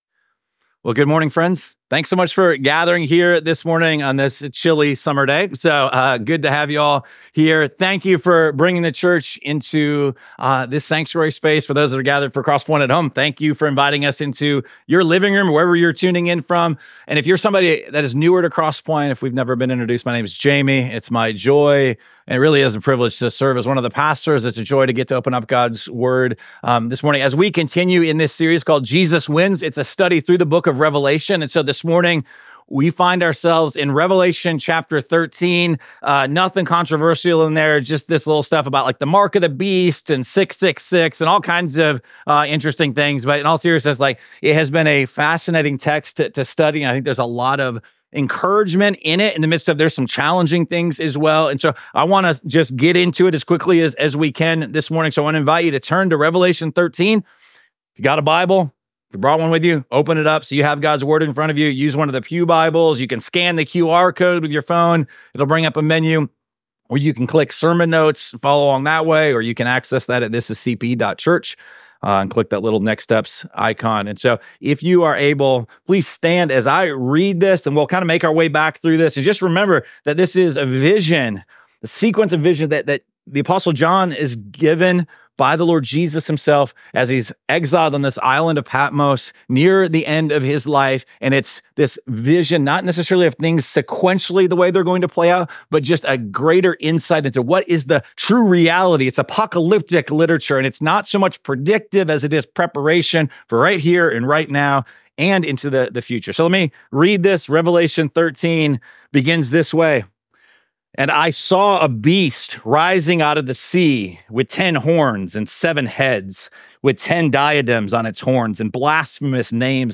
Week 9 of our series Jesus Wins: A Study of Revelation. This sermon comes from Revelation chapters 13.